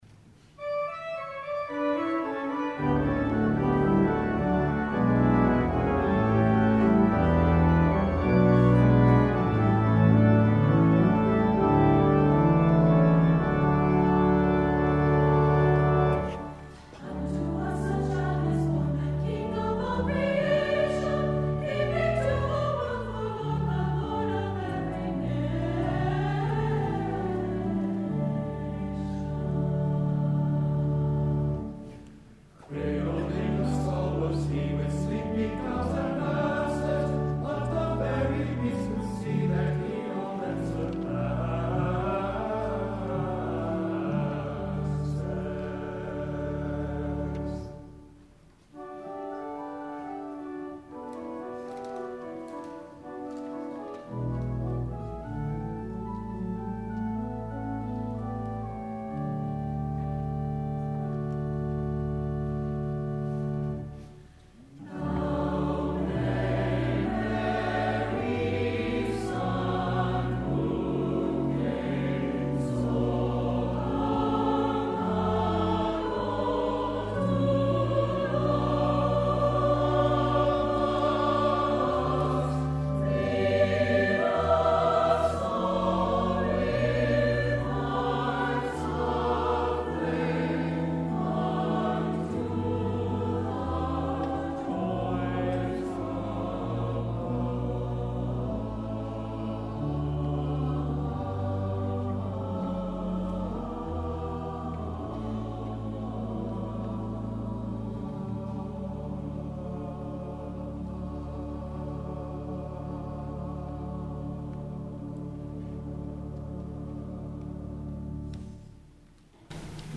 Number of voices: 4vv Voicing: SATB Genre: Sacred, Anthem
Language: English Instruments: Organ